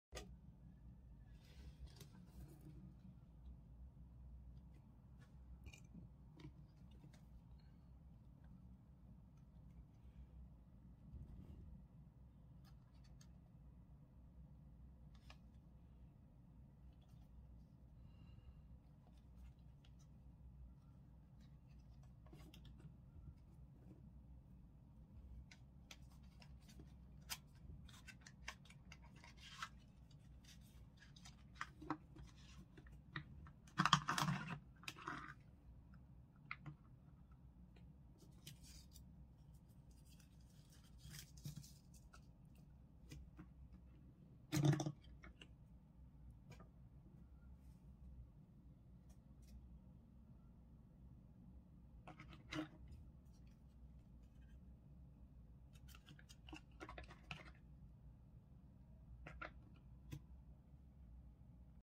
[Samsung][Galaxy SmartTag][Unboxing]Samsung Galaxy SmartTag Unboxing sound effects free download